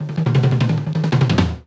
Drum Fills (4).wav